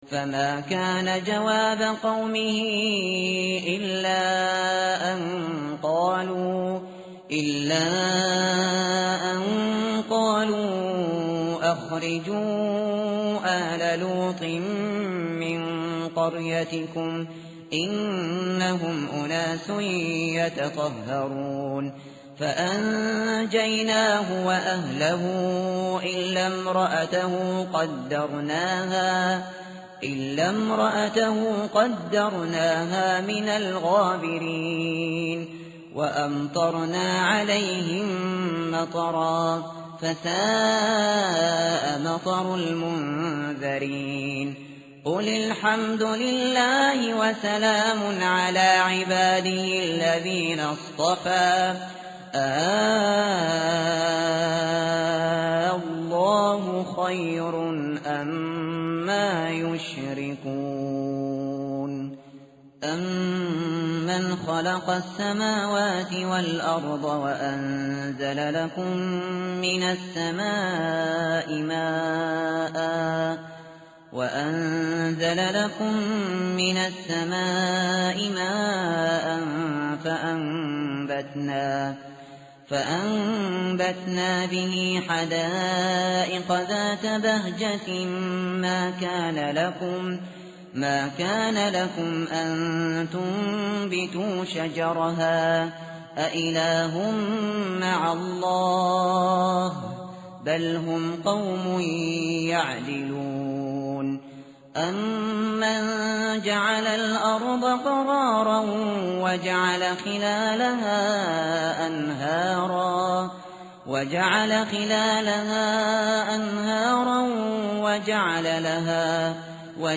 متن قرآن همراه باتلاوت قرآن و ترجمه
tartil_shateri_page_382.mp3